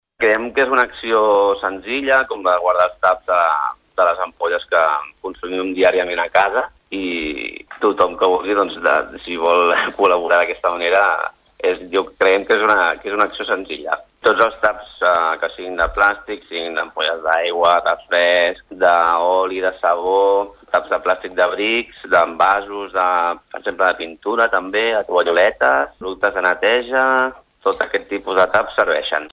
Són declaracions a Nova Ràdio Lloret.